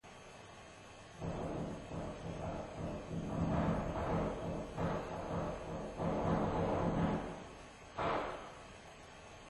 camera which was at the top of the middle stairway:
Then upon listening more, this may actually be a drum beat of some sort.